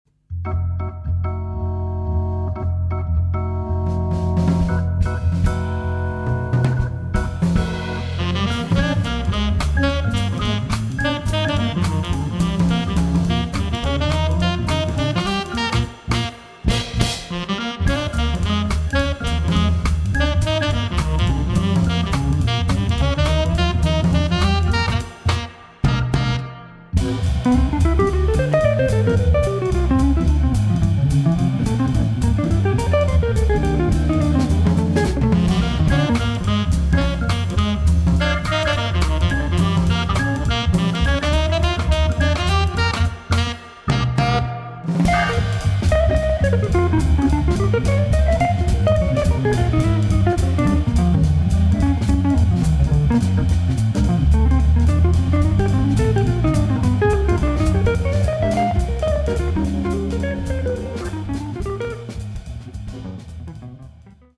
hammond